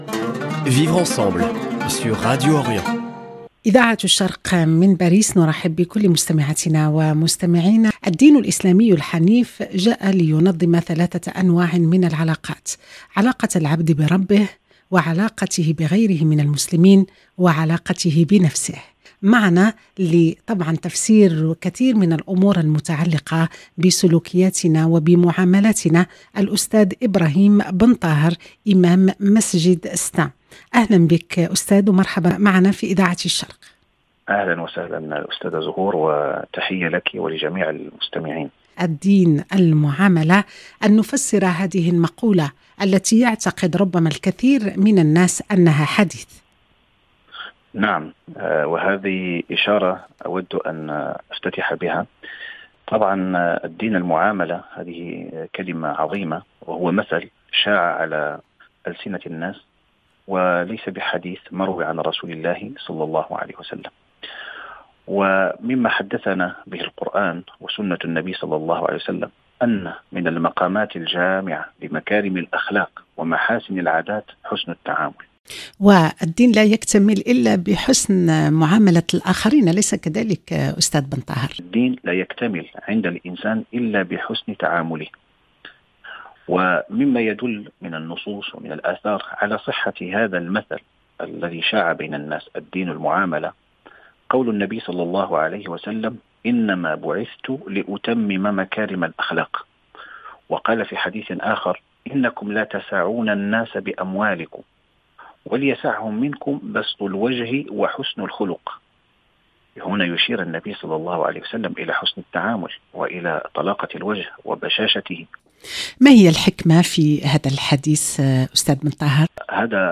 أسئلة كثيرة نطرحها على ضيفنا الكريم في هذا الحوار